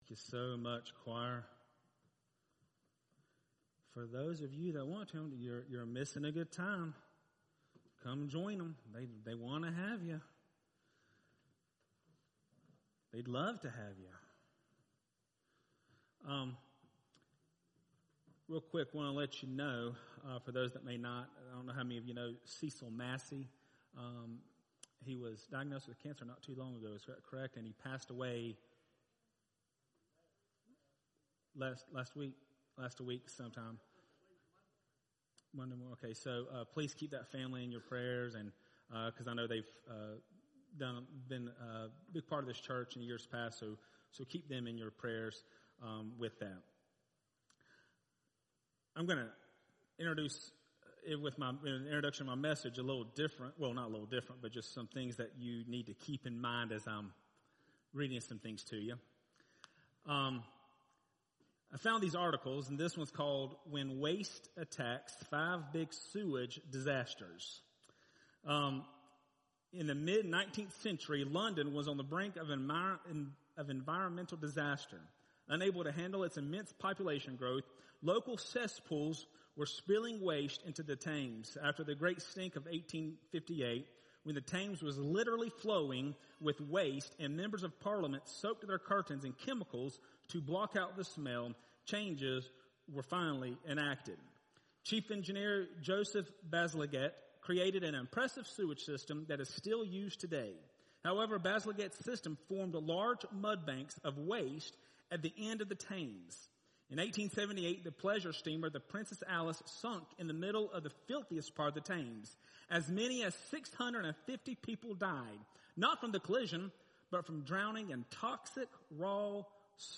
Archive Sermons